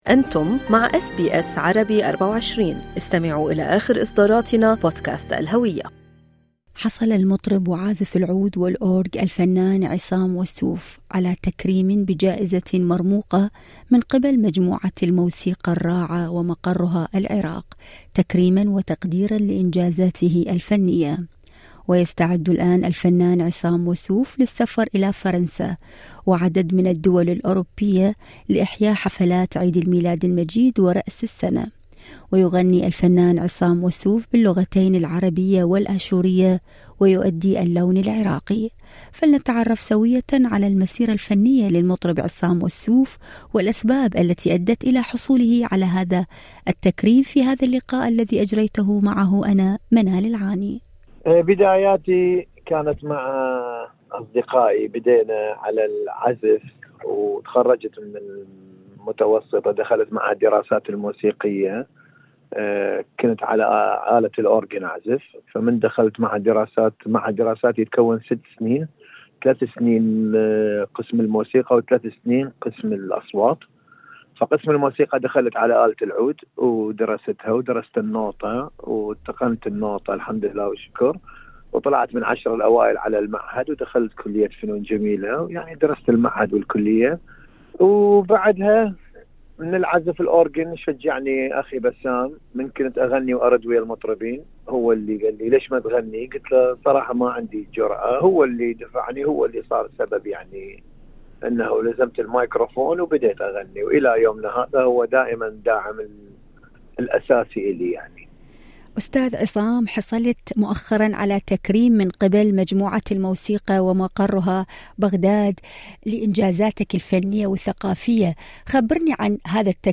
المزيد في المقابلة الصوتية اعلاه هل أعجبكم المقال؟